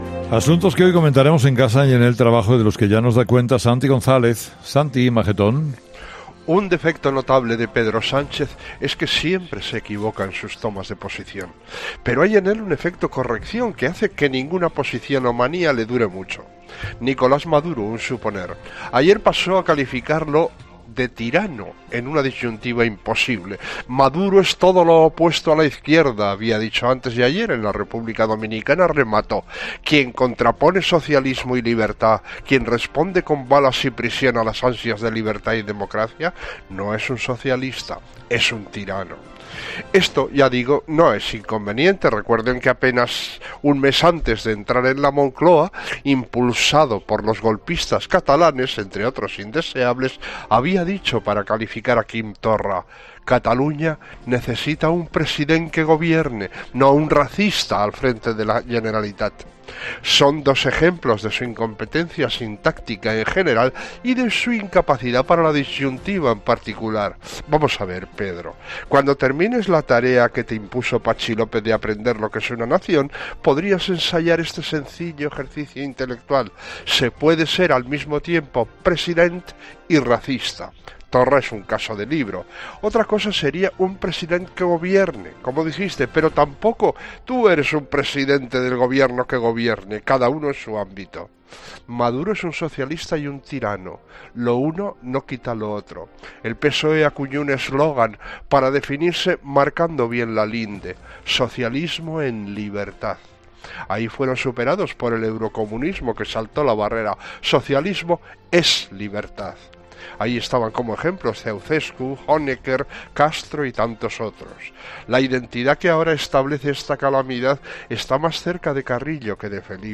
El análisis de actualidad de Santi González en 'Herrera en COPE'.